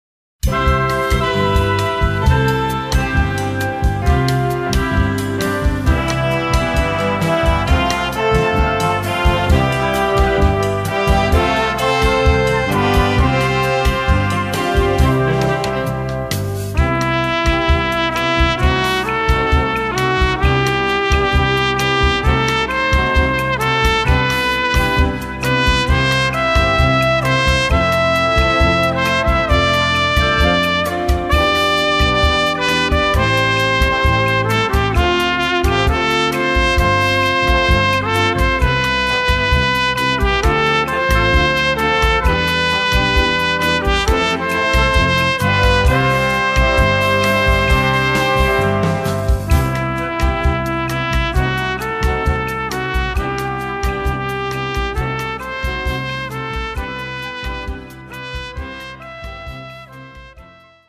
Instrumentalnoten für Saxophon